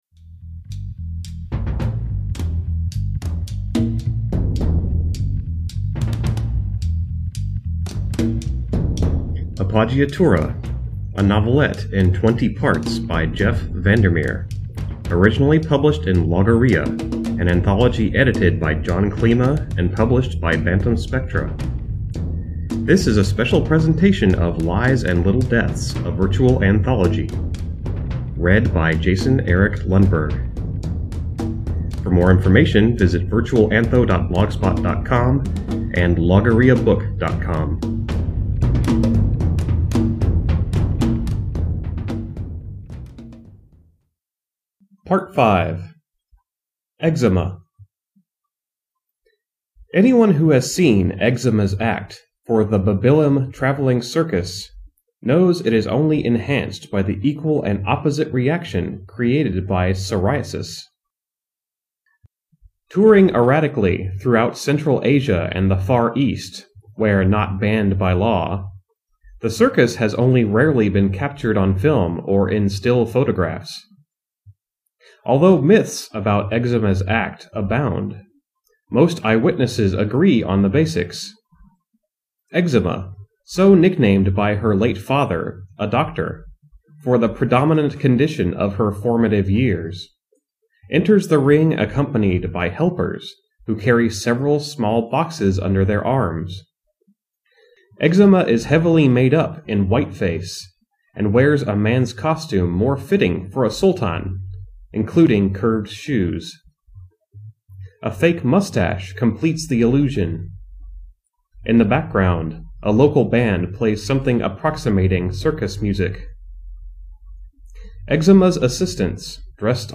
Music provided by The Church, and is used with permission.